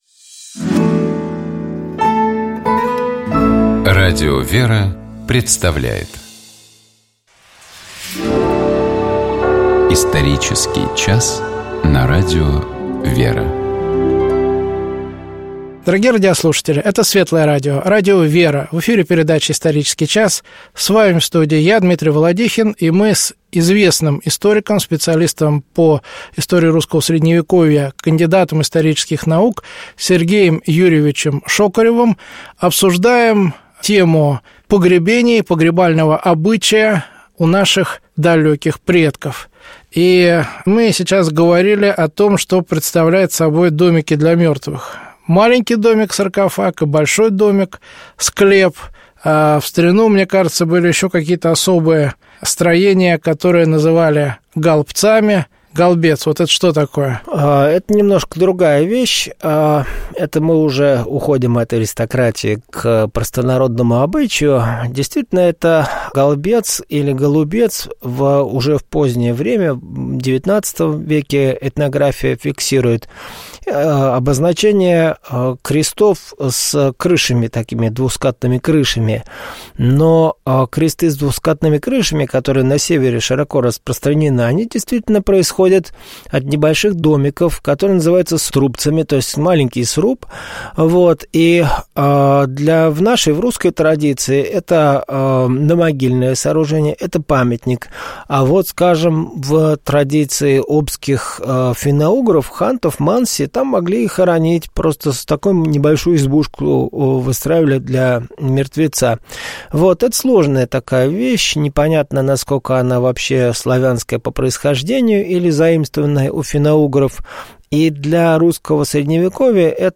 Гость программы: кандидат исторических наук